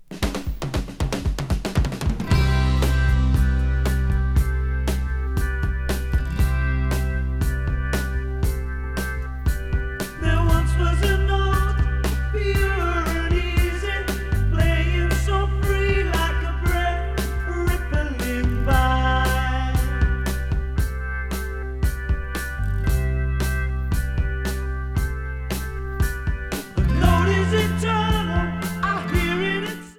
Unbelievable clarity and power.
1980 Japan LP